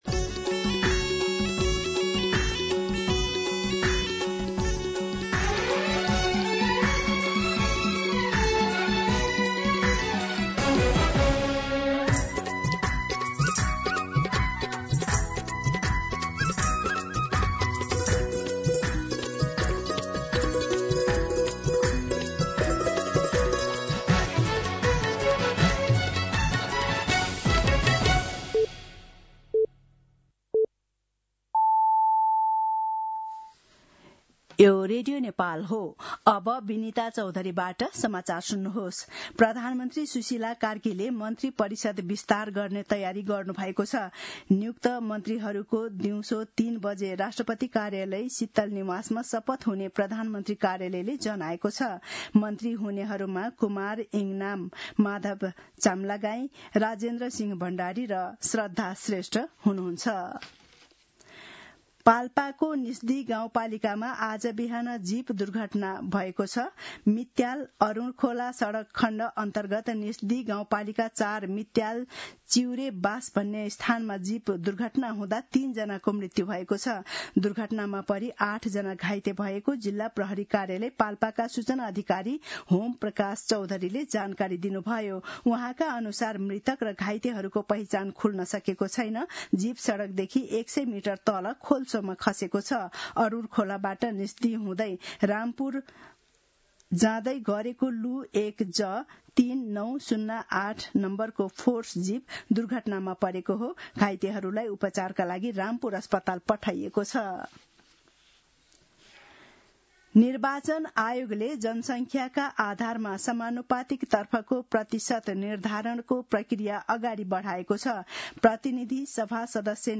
दिउँसो १ बजेको नेपाली समाचार : २६ मंसिर , २०८२
1pm-News-8-26.mp3